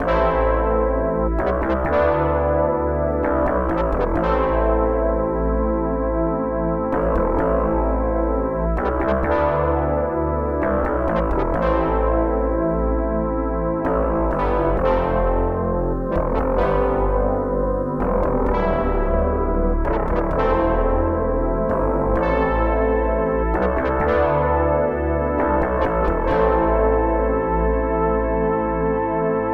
A simple loopable melody made with a DX-7 clone